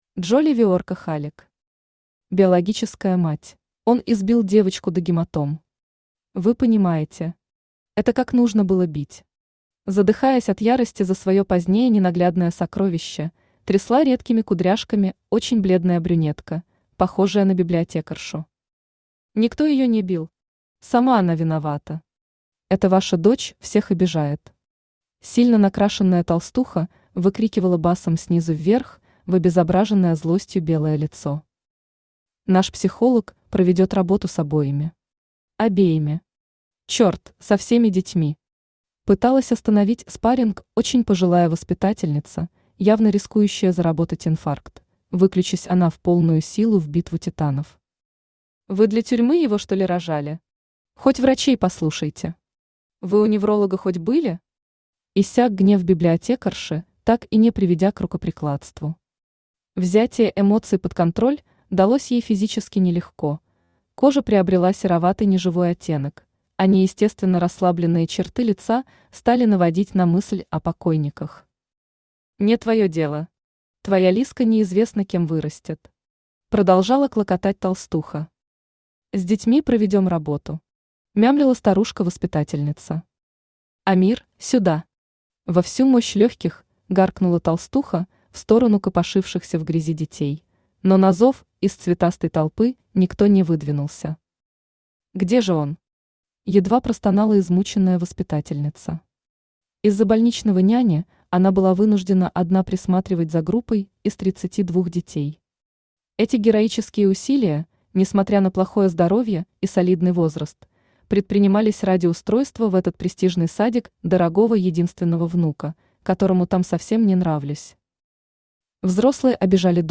Читает: Авточтец ЛитРес
Аудиокнига «Биологическая мать».